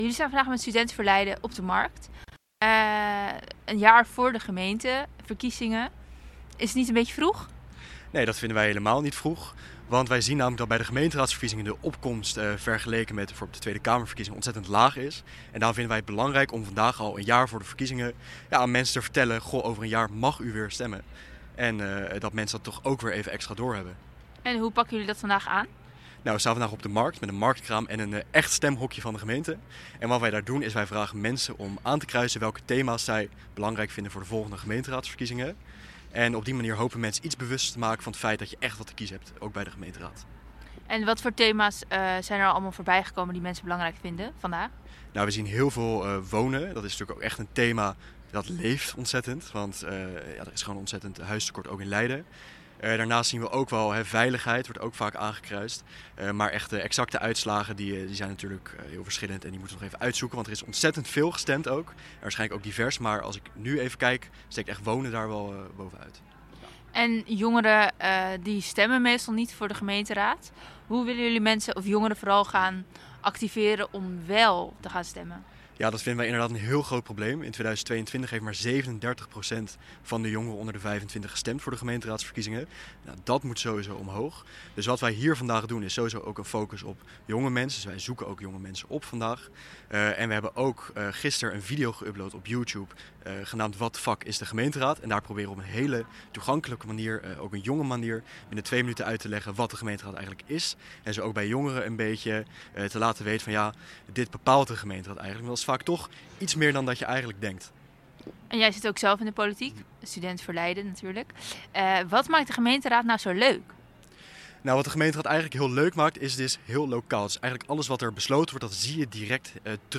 Op woensdag 19 maart stond de partij op de Leidse markt om inwoners, en met name jongeren, te betrekken bij de lokale politiek.